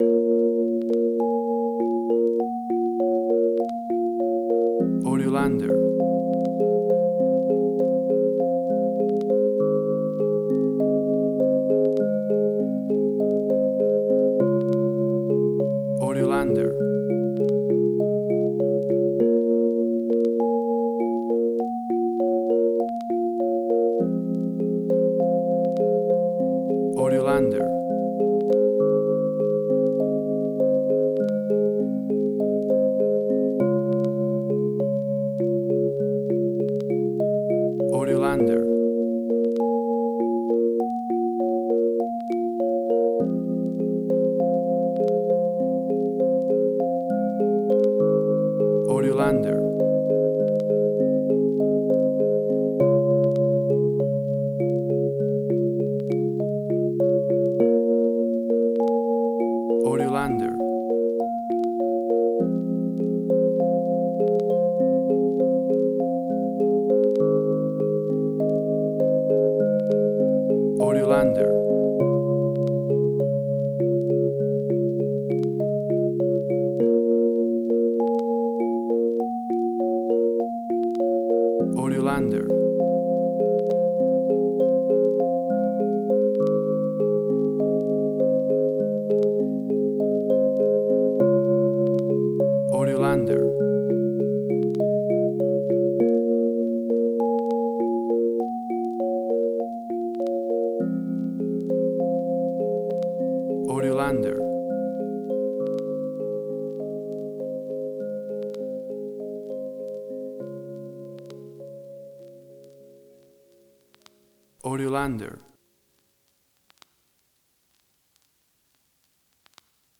Suspense, Drama, Quirky, Emotional.
Tempo (BPM): 50